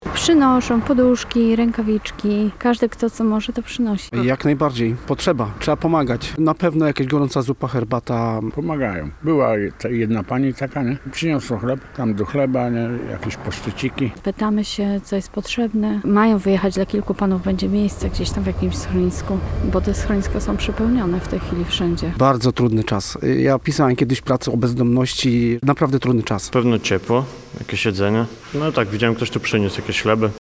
Posłuchaj wypowiedzi osób pomagających i tych, które z tej pomocy korzystają: